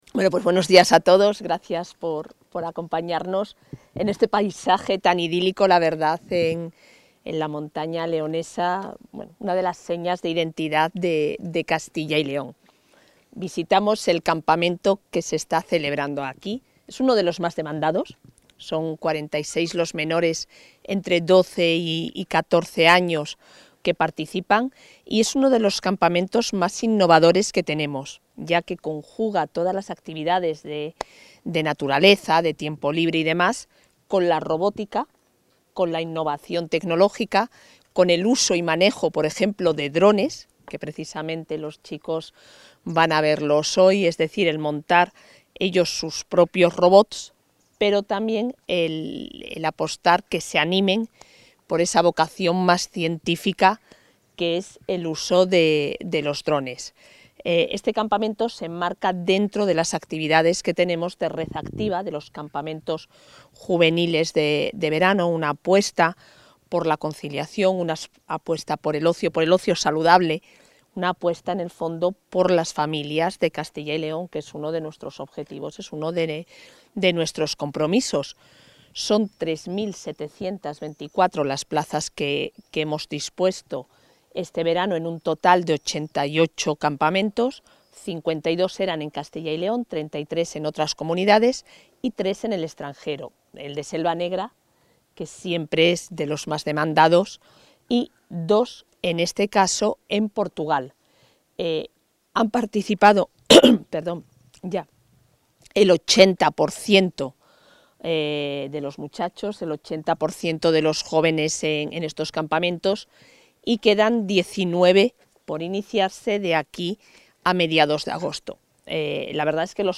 Declaraciones de la vicepresidenta de la Junta.